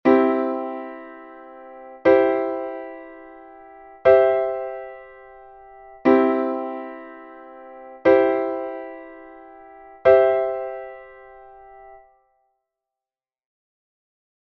Exemplo: na tonalidade de Do M, o acorde na súa posición fundamental terá que comezar en Do e despois por 3ª (Do-Mi-Sol):